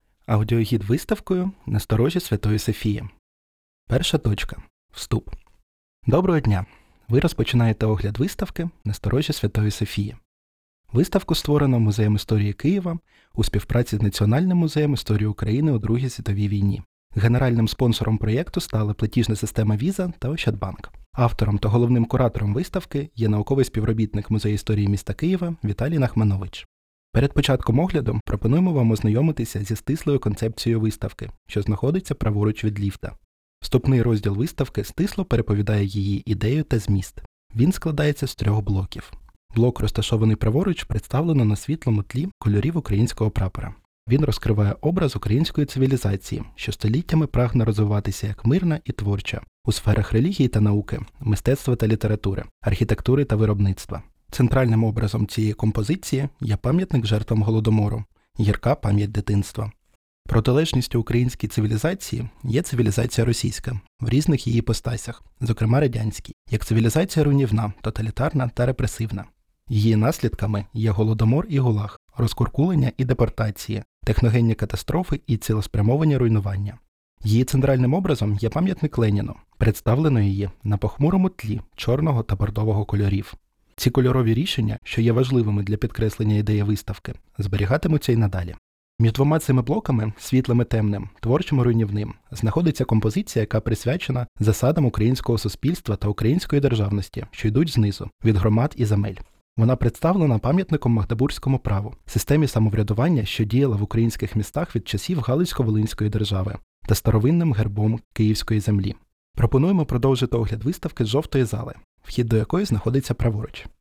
Аудіогід 1 точка Вступ.wav